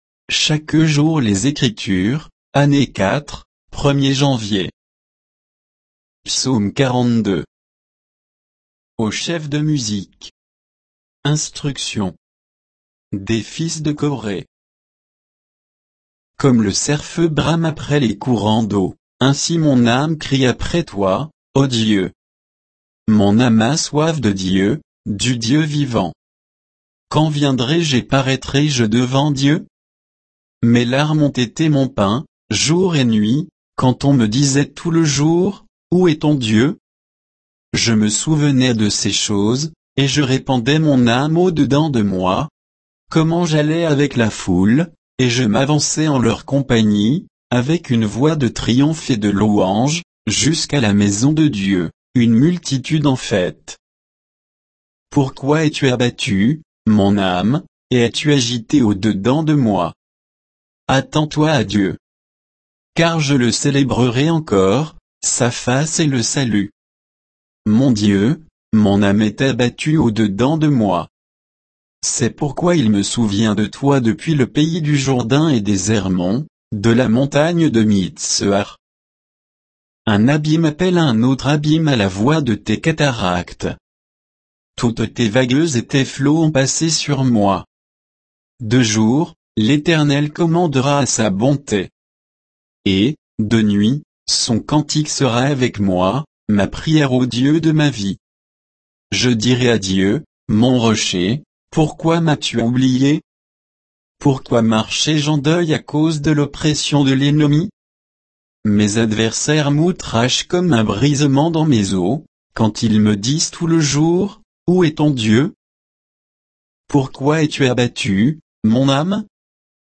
Méditation quoditienne de Chaque jour les Écritures sur Psaume 42